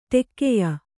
♪ ṭekkeya